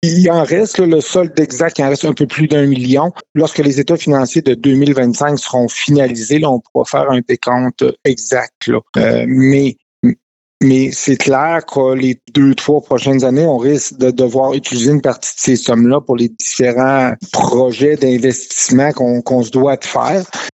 En raison de l’entretien et des améliorations qui sont nécessaires sur les infrastructures municipales, Murdochville prévoit utiliser plus de 292 000 dollars du surplus cumulé non affecté. Stéphane Gamache explique aussi l’affectation de ce montant par l’augmentation des dépenses incompressibles :